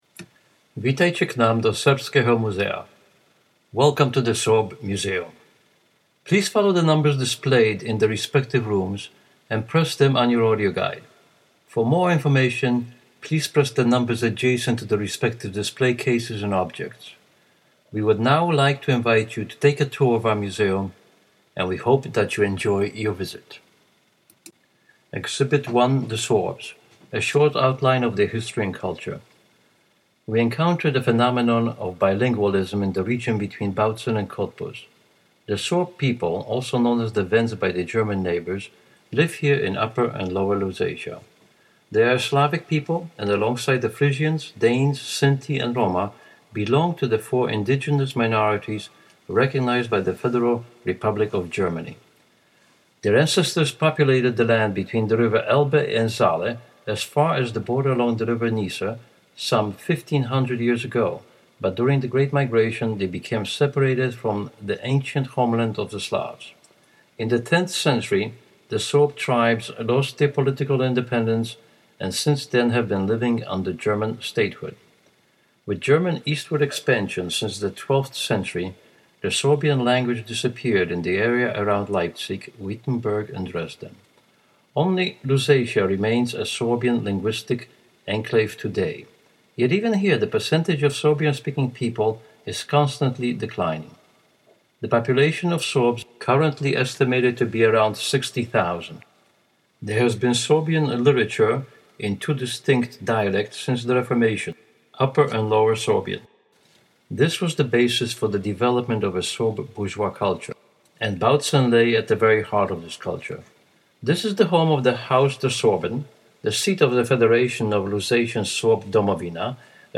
Audioguide - Sorbisches Museum Bautzen